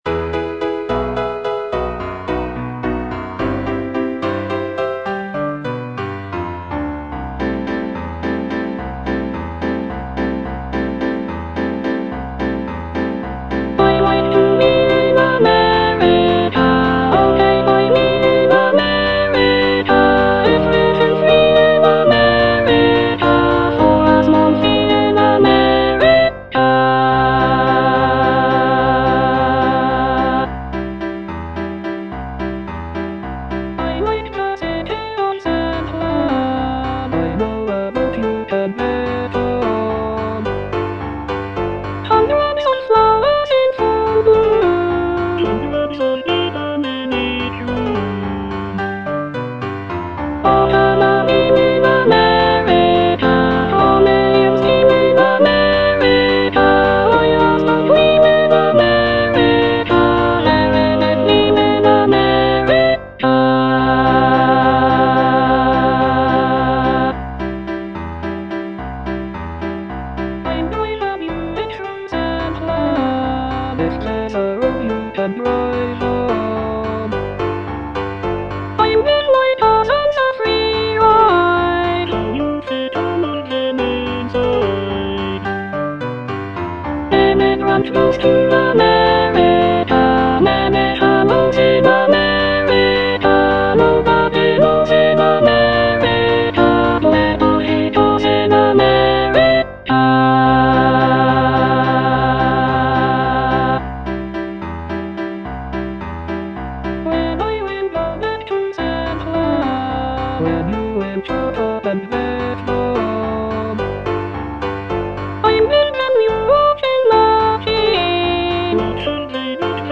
Choral selection
Soprano (Emphasised voice and other voices) Ads stop